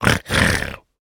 Minecraft Version Minecraft Version snapshot Latest Release | Latest Snapshot snapshot / assets / minecraft / sounds / mob / piglin / celebrate1.ogg Compare With Compare With Latest Release | Latest Snapshot
celebrate1.ogg